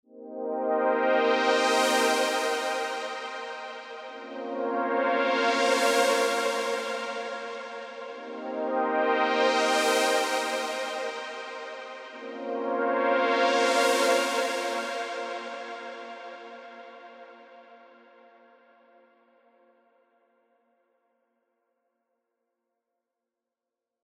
今回チョイスした「Unity」のように派手目なサウンドのパッド系音色は、音の立ち上がりがゆっくりで、余韻が長いものが多く、白玉コードなどで使用するには良いのですが、リズミックなコードリフは演奏し辛い傾向があります。
※ フレーズを分かりやすくするために、キックの4つ打ちを加えています。
サンプル：Before（プリセットを未エディットの状態で演奏した白玉コードトラック）
ポリフォニック・シンセサイザー